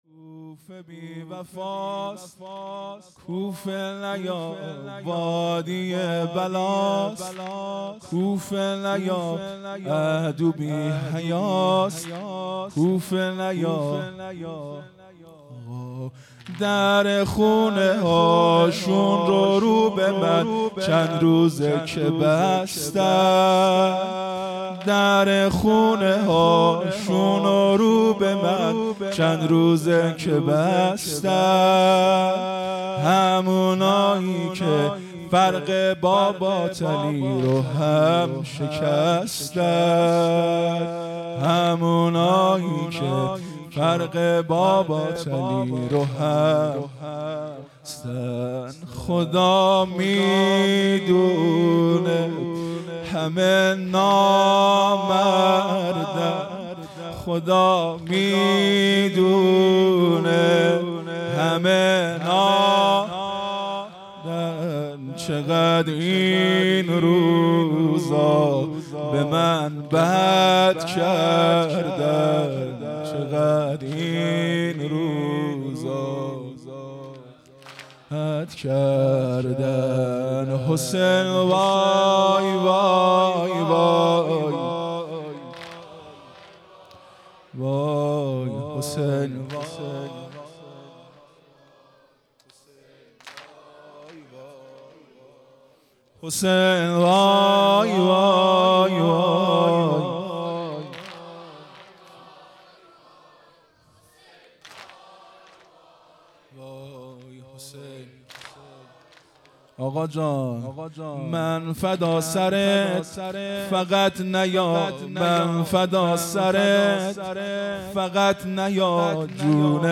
محرم ۱۴۴۵_شب اول